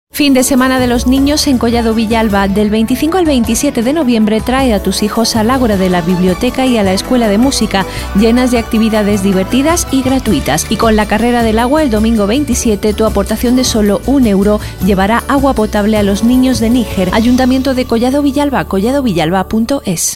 spot-dia-del-nino.mp3